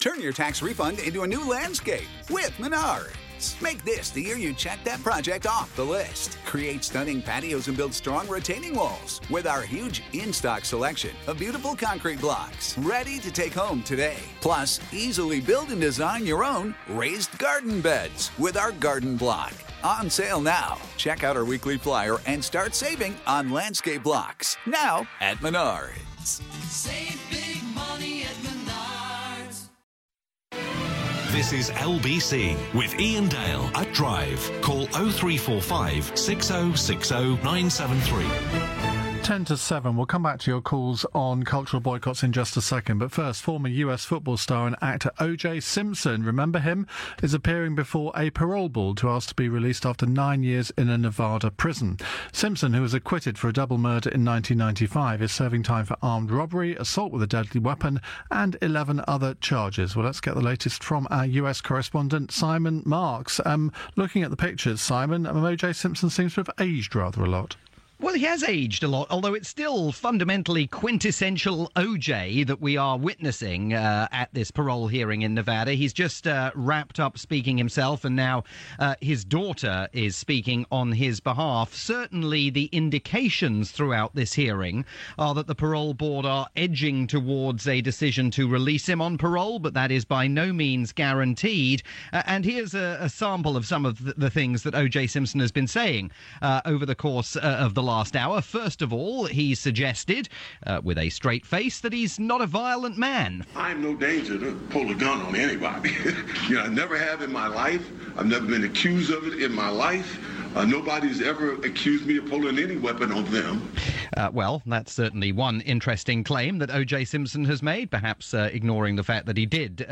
live report from "Iain Dale at Drive" on the UK's LBC.